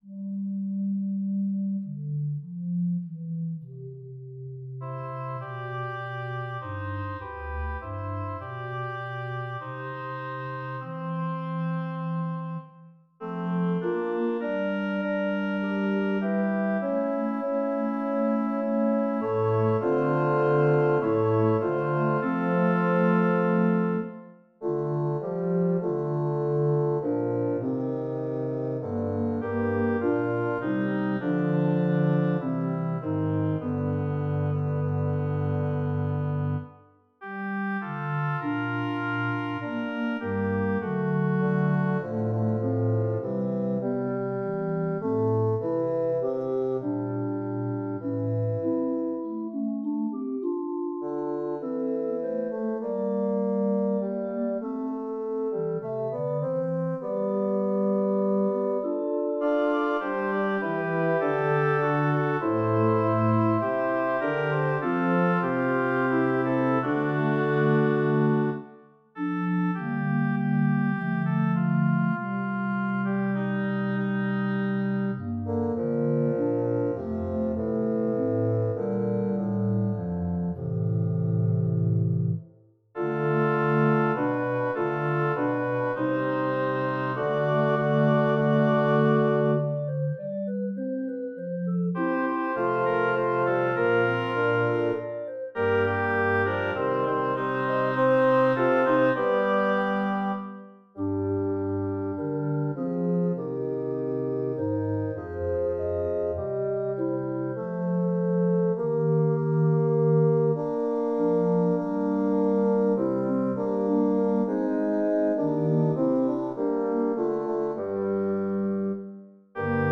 Number of voices: 4vv Voicing: SATB Genre: Sacred, Evening Canticles
Language: English Instruments: Organ
Score information: Letter, 3 pages, 66 kB Copyright: CC BY NC Edition notes: SATB and Organ.
MP3 is a MIDI recording.